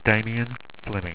Pronounced
DAY-ME-AN FLEM-ING